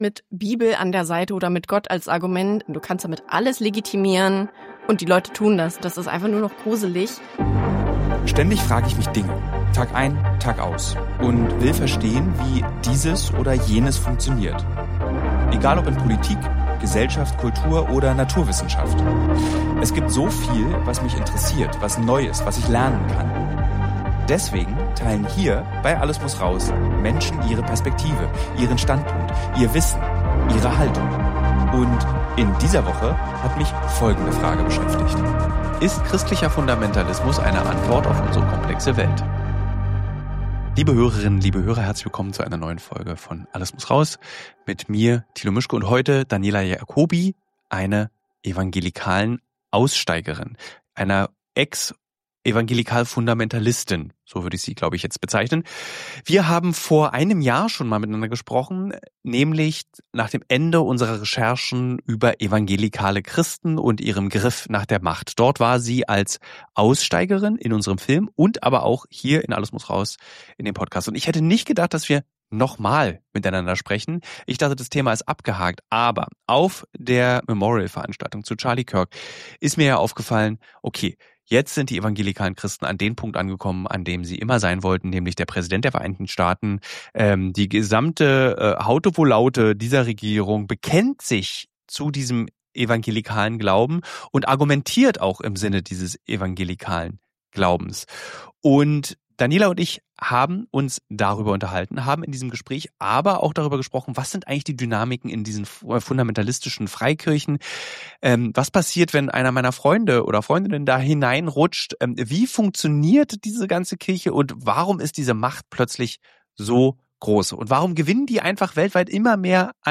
Mit Thilo diskutiert sie, warum evangelikale Christen Parallelen zu anderen extremistischen Bewegungen haben, wie gefährlich ihr Einfluss weltweit werden könnte und warum einfache Antworten in einer komplexen Welt so viele Menschen anziehen. Ein Gespräch über Macht, Religion, Social Media – und die Frage, wie wir als Gesellschaft damit umgehen.